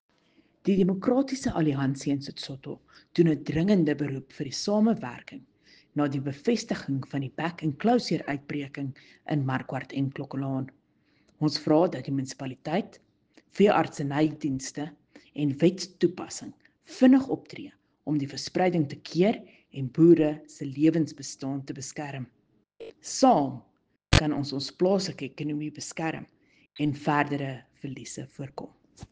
Afrikaans soundbites by Cllr Riëtte Dell and Sesotho soundbite by Jafta Mokoena MPL.